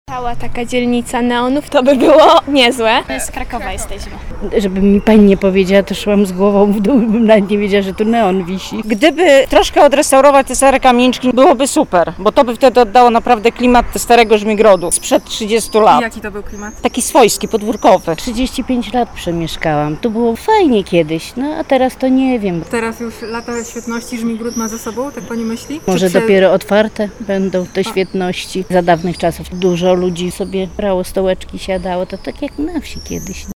Napotkanych mieszkańców zapytaliśmy, jak postrzegają tę lubelską ulicę:
Mieszkańcy